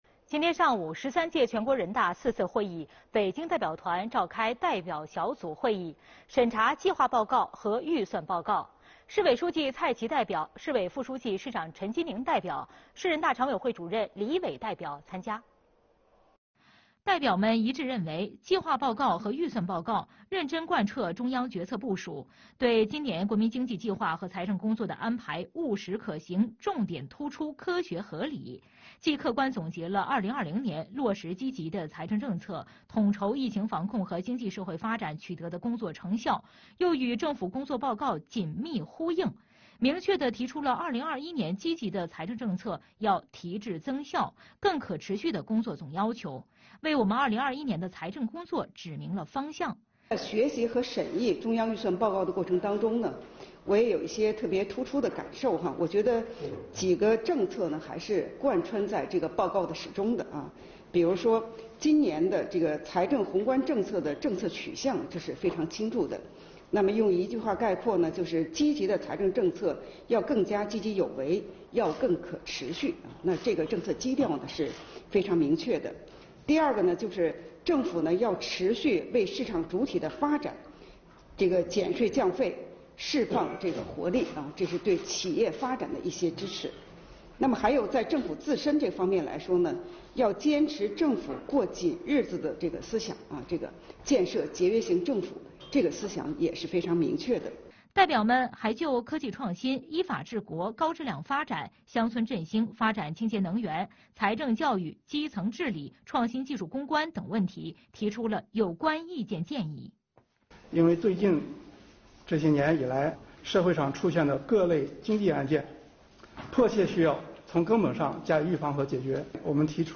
全国两会特别报道北京代表团审查计划报告和预算报告